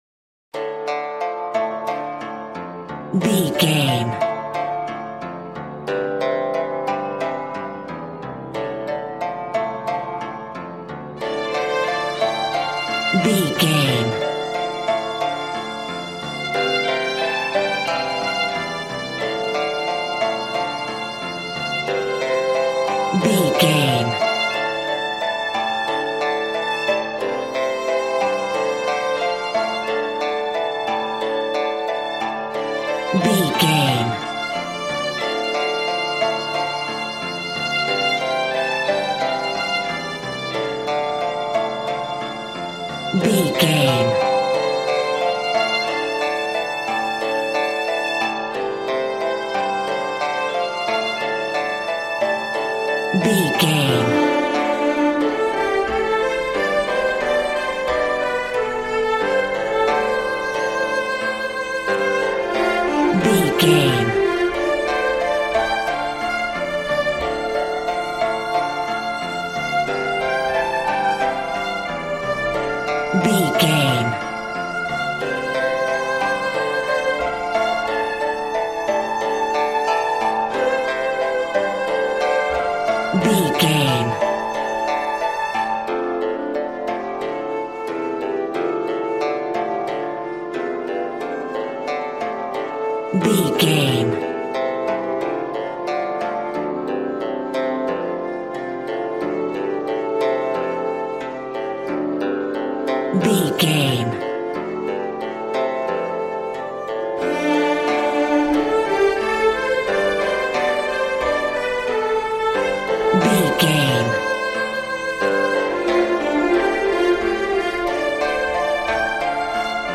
Aeolian/Minor
happy
bouncy
conga